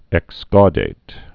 (ĕk-skôdāt)